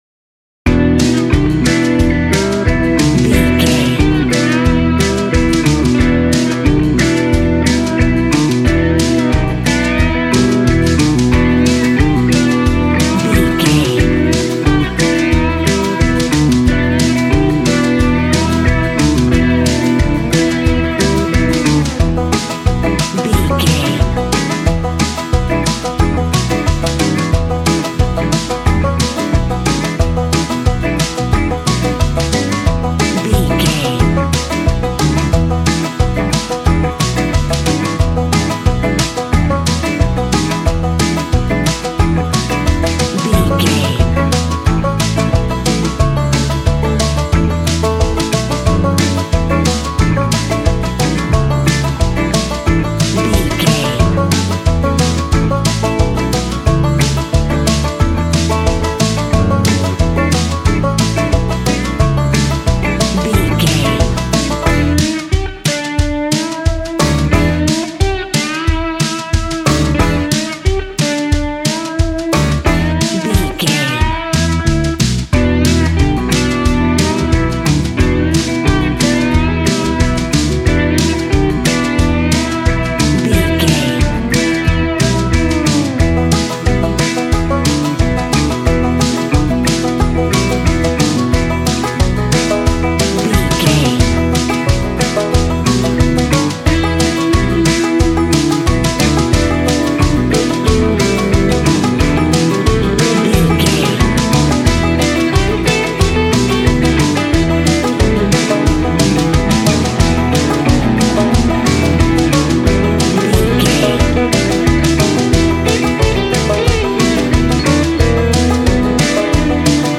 Classic country music for a rodeo cowboy show.
Ionian/Major
Fast
bouncy
positive
double bass
drums
acoustic guitar